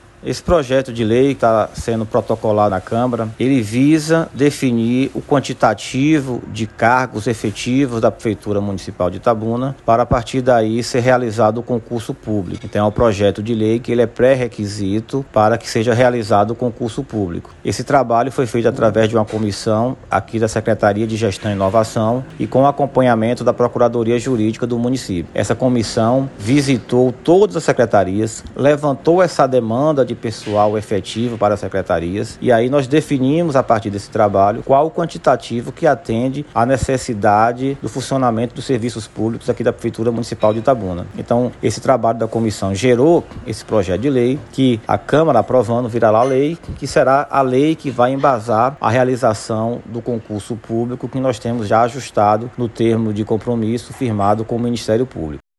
No áudio abaixo, o secretário de Gestão e Inovação, José Alberto de Lima Filho, explica o porquê das mudanças.